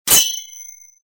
stageclear.mp3